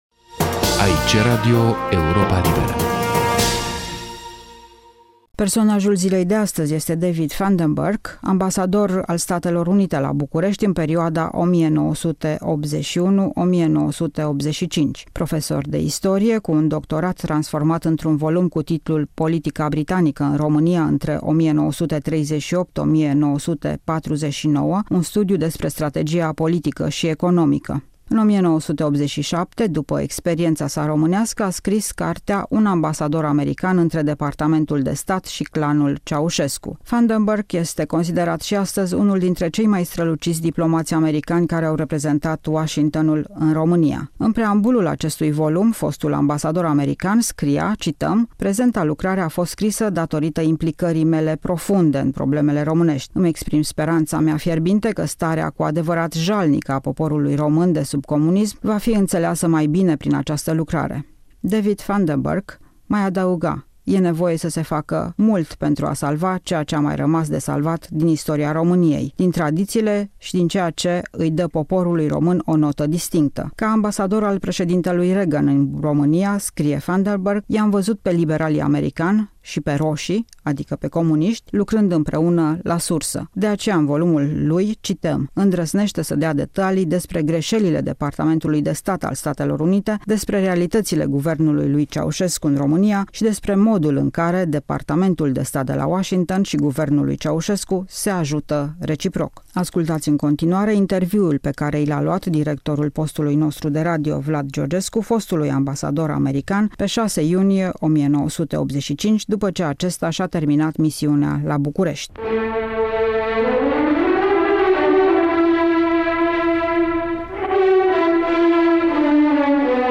Vlad Georgescu în dialog cu David Funderburk, ambasador american la București între 1981-1985.